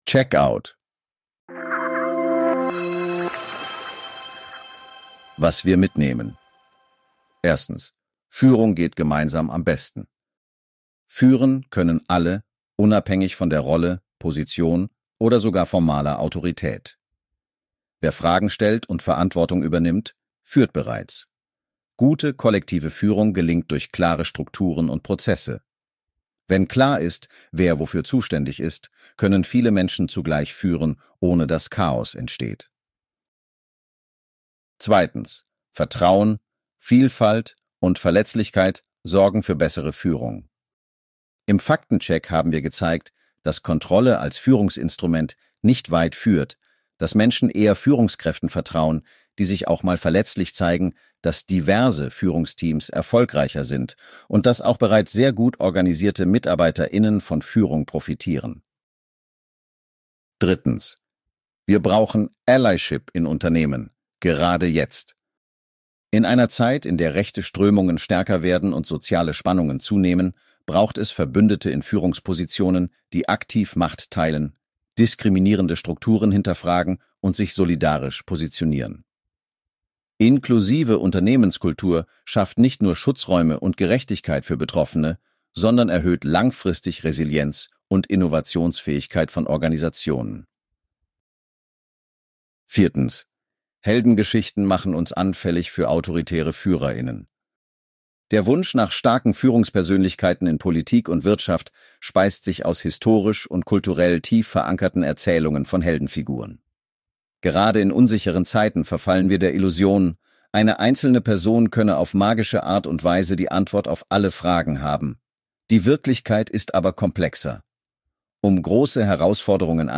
Diesen Beitrag liest eine erfundene Stimme vor, die Redakteur*innen